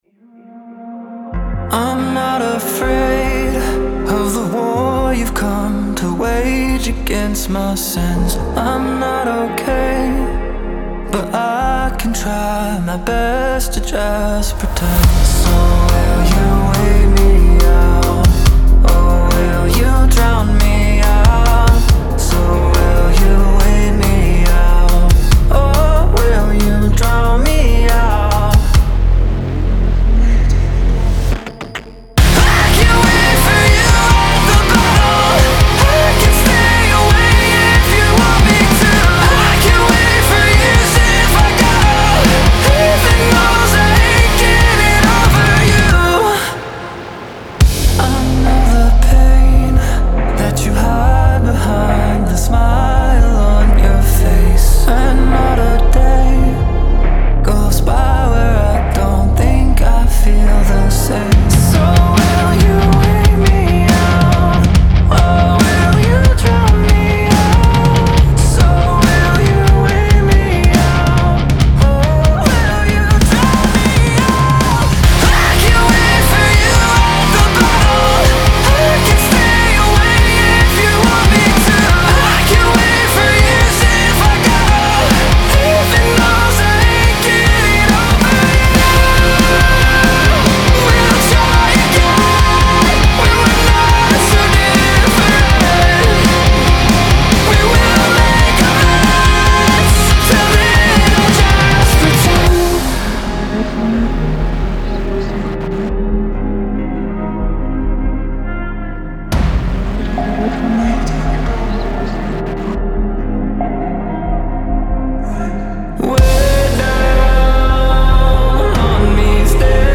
Genre: metalcore